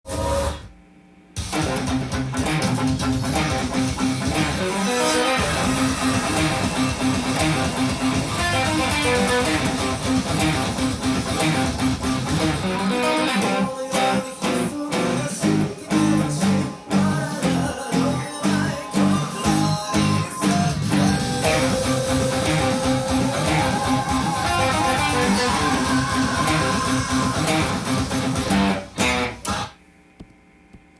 音源にあわせて譜面通り弾いてみました
最初のヘビィーなリフがありますが
ここからすでに16分音符でオルタネイトピッキングで弾きながら